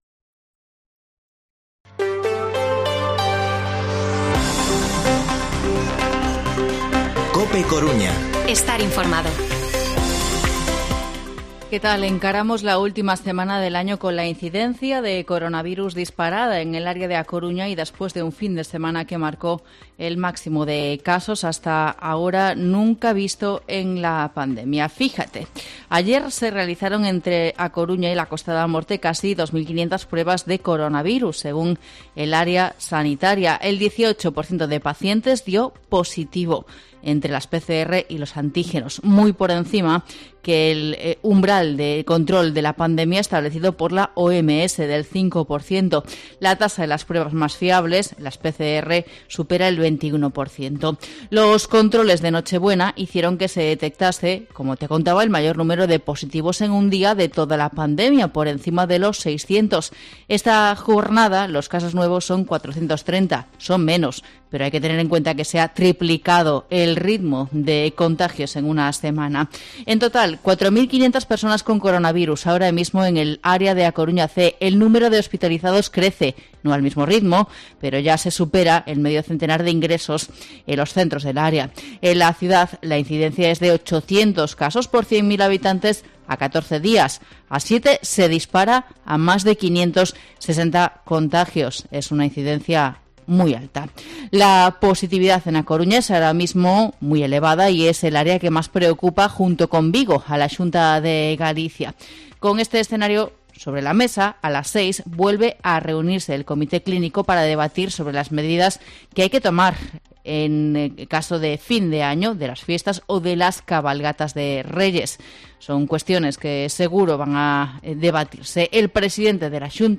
Informativo mediodía COPE Coruña lunes, 27 de diciembre de 2021